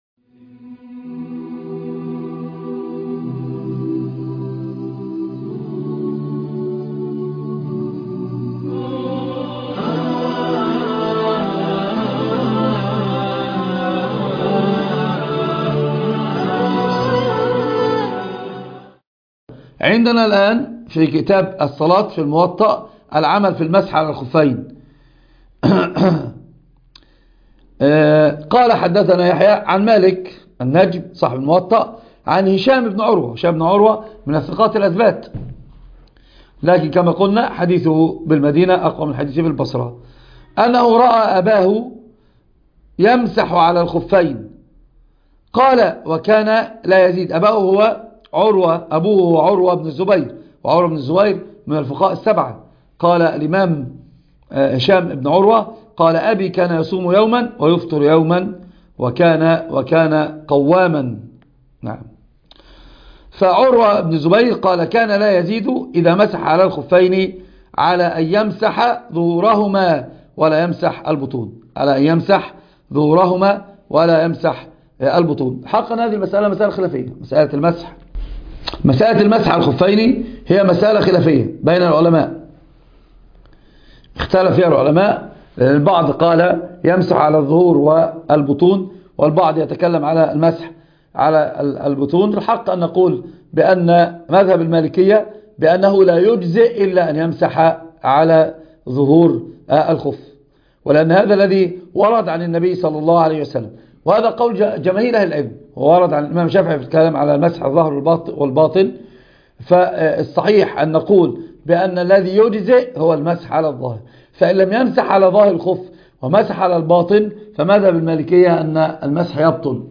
الحديث وعلومه     شرح الأحاديث وبيان فقهها